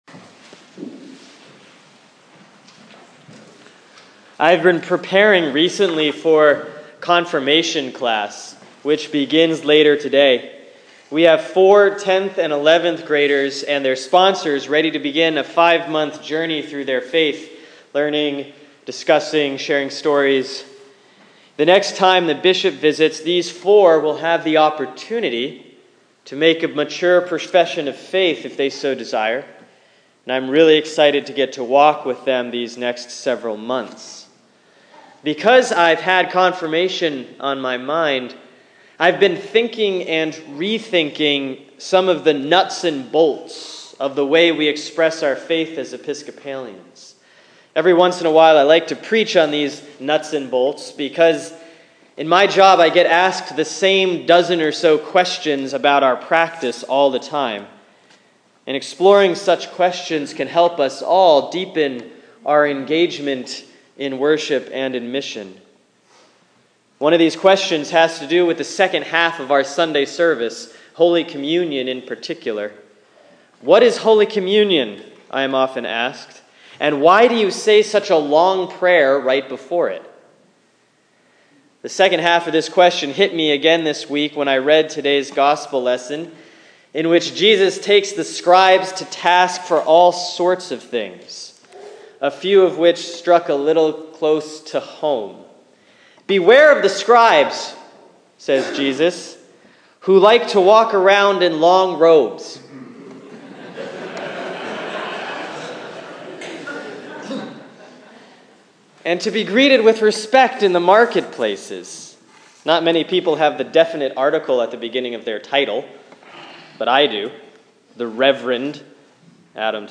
Sermon for Sunday, November 8, 2015 || Proper 27B || Mark 12:38-44